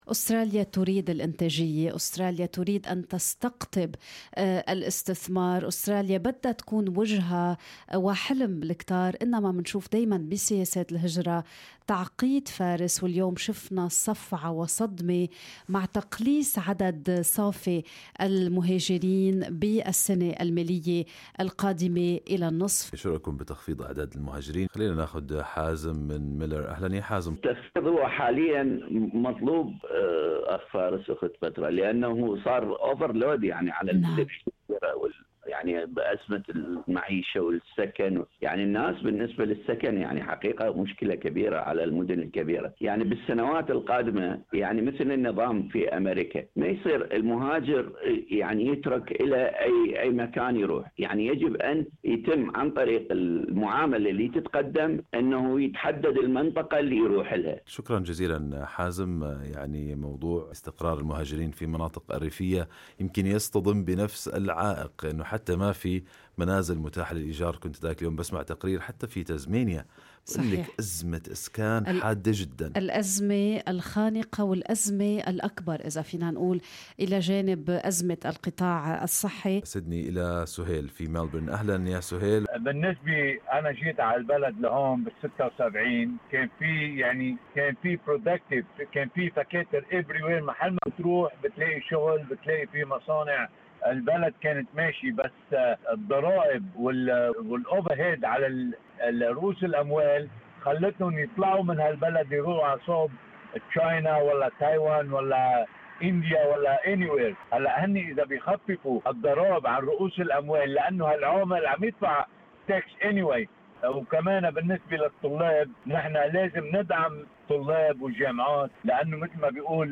سلمت الحكومة الفيدرالية ميزانيتها في 14 مايو 2024، وفيها قدمت العديد من التدابير التي سيكون لها آثار على برنامج الهجرة إلى البلاد ومن أهمها خفض عدد التأشيرات. سألنا أفراداً من الجالية العربية عن هذه التغييرات ضمن فقرة الحوار المباشرة في برنامج "صباح الخير أستراليا".